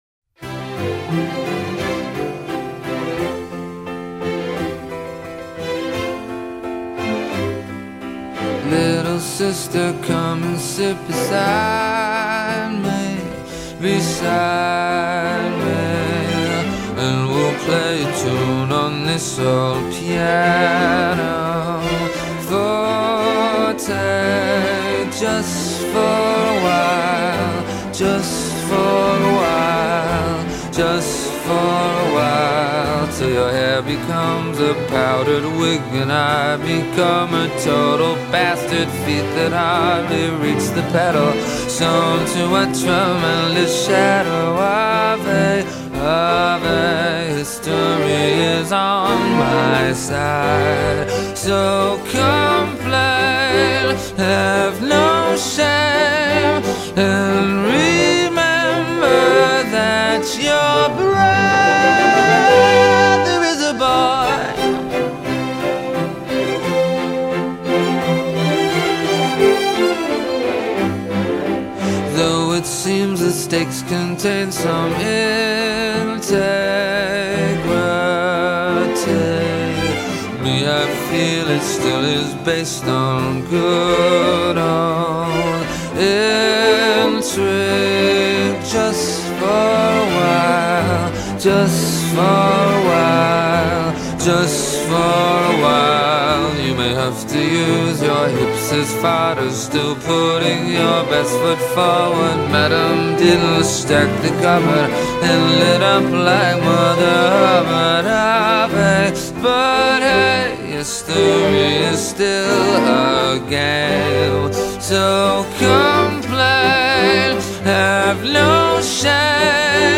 art pop rock
into a nifty little bit of chamber pop.